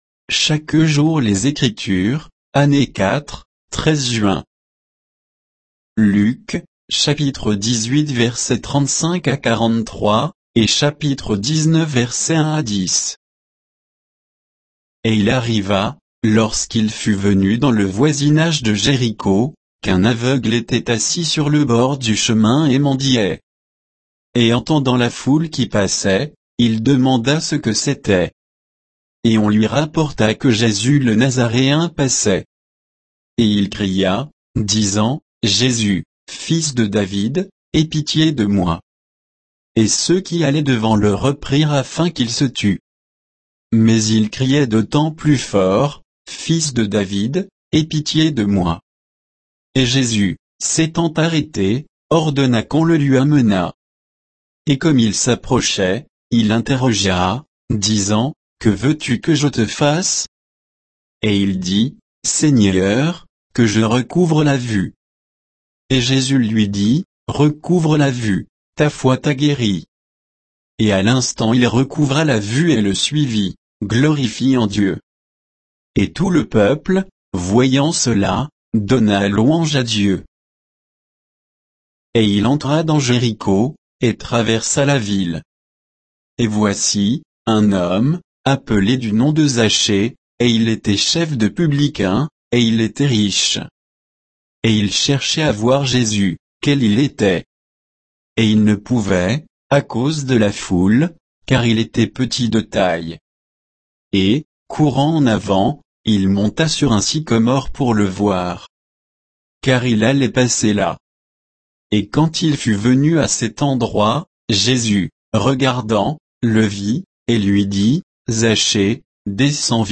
Méditation quoditienne de Chaque jour les Écritures sur Luc 18, 35 à 19, 10